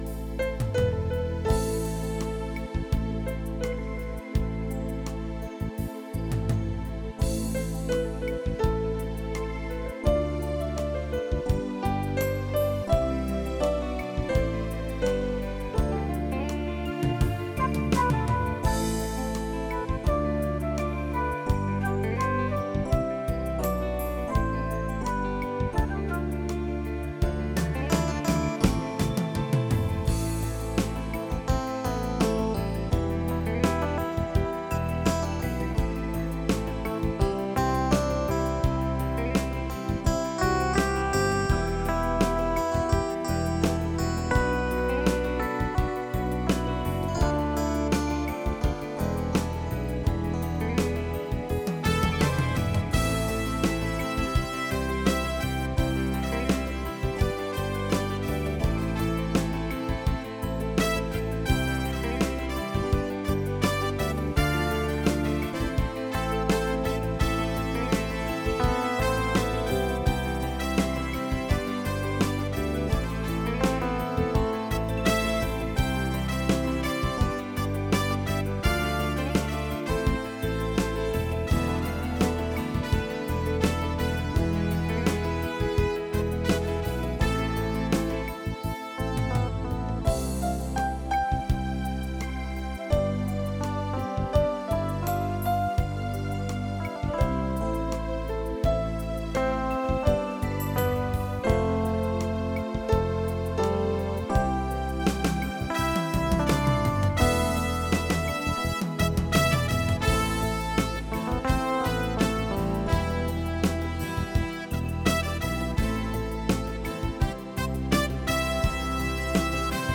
קטע נגינה חביב באורגן